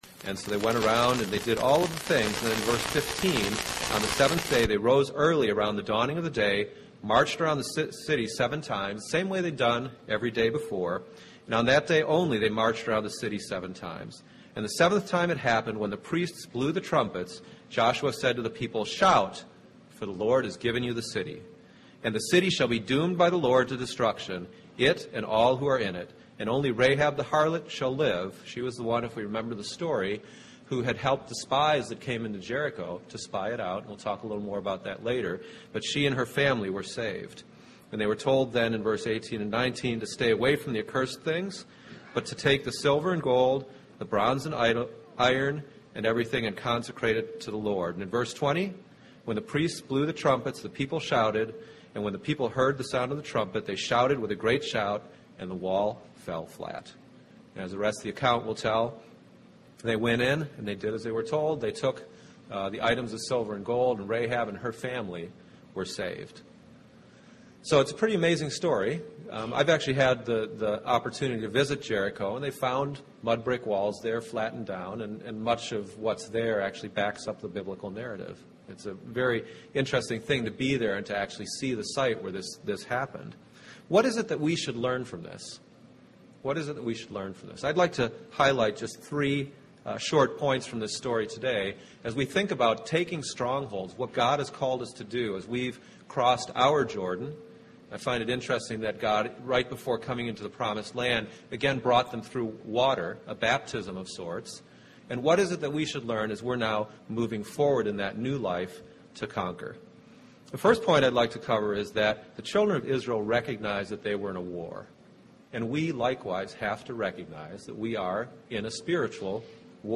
In this sermon we will look at three points that can help us to overcome the strongholds we all face in our lives.